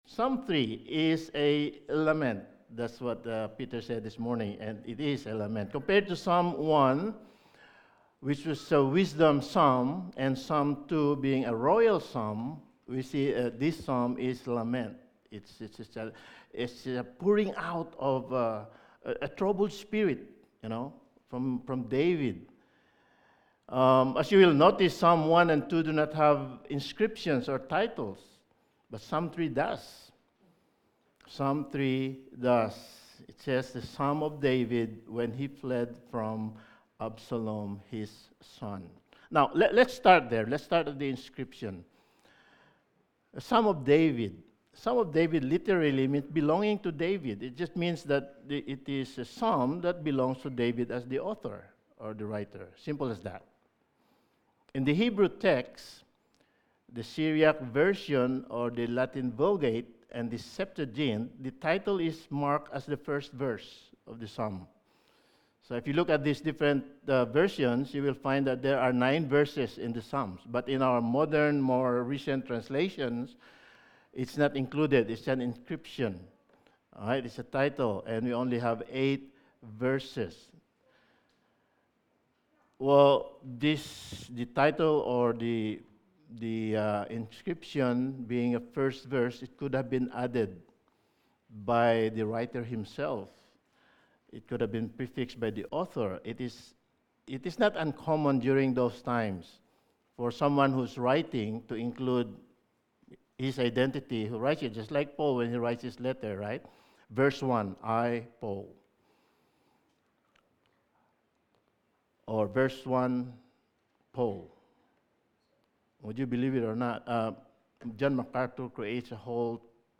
Sermon
Psalm 3:1-8 Service Type: Sunday Morning Sermon 4 « Psalm 23